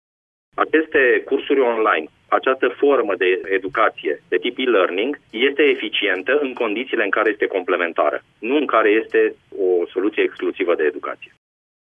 Reprezentanţii Inspectoratului Şcolar Judeţean Braşov spun, însă, că aceste cursuri online sunt eficiente doar dacă sunt privite ca metode complementare de educaţie, pentru că nu pot înlocui, sub nicio formă, învăţământul tradiţional: